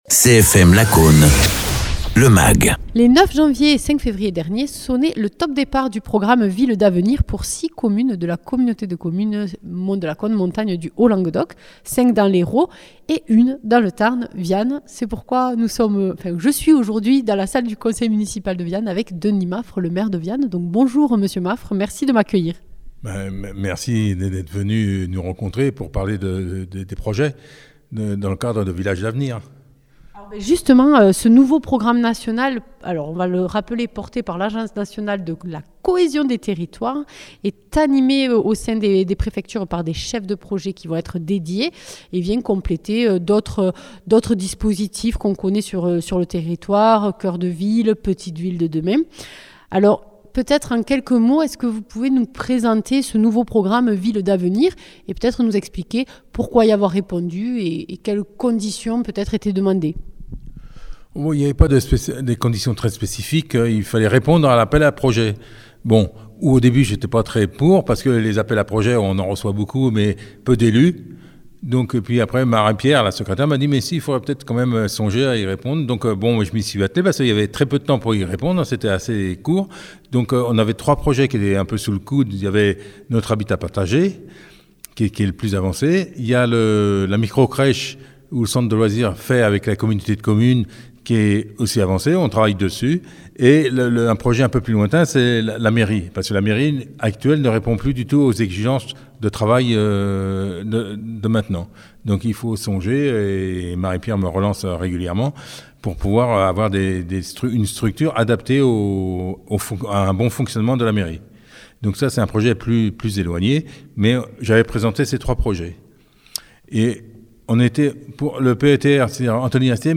Ce nouveau programme national vient compléter d’autres programmes locaux. Rencontre avec Denis Maffre, maire de Viane pour savoir quels sont les objectifs pour le village.
Interviews